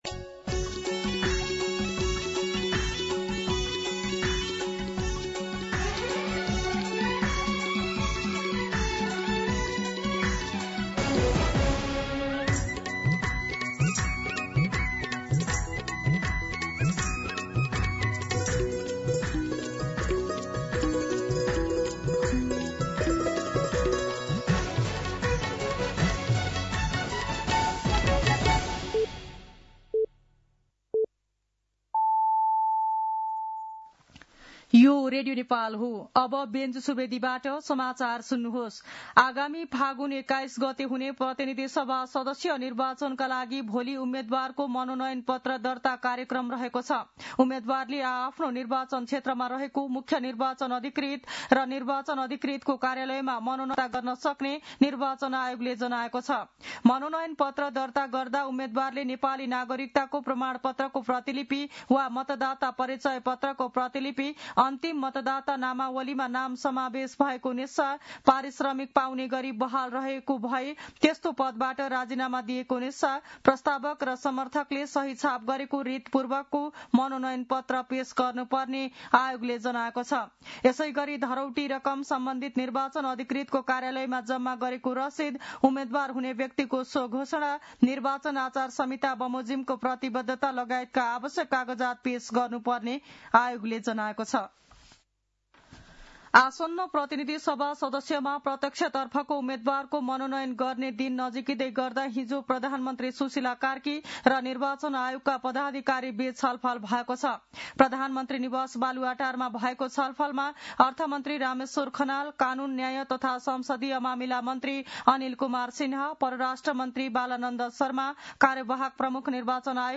मध्यान्ह १२ बजेको नेपाली समाचार : ५ माघ , २०८२